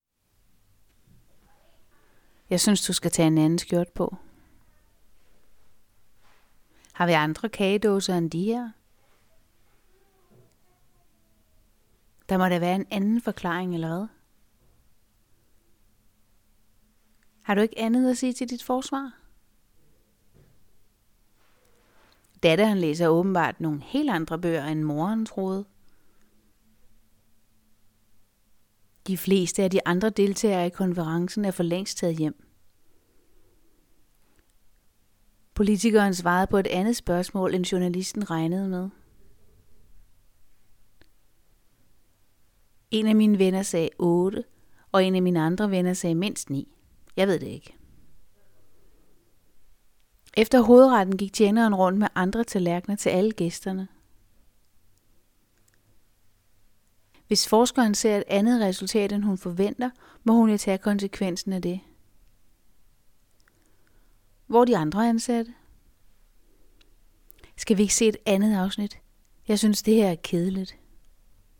Bemærk forskellen i udtalen på a’erne, anden og andet udtales med[a], andre udtales med [ɑ].